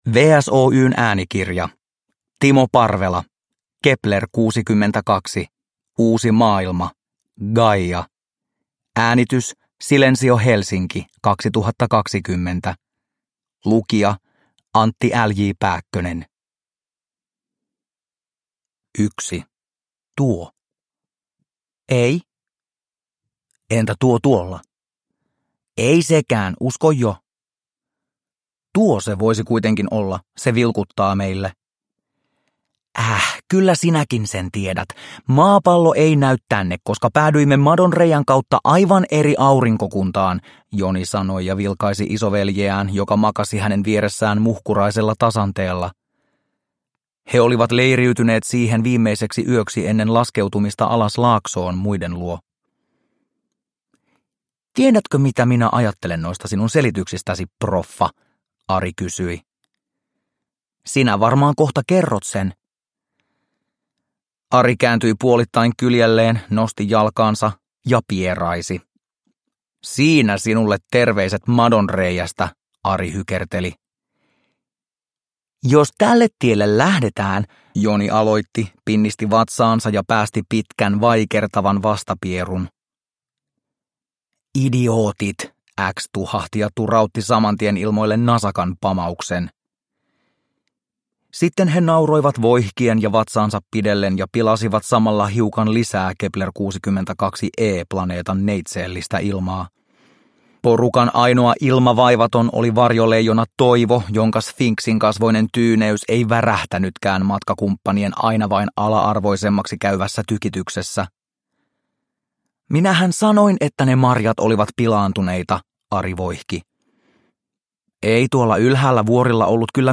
Kepler62 Uusi maailma: Gaia – Ljudbok – Laddas ner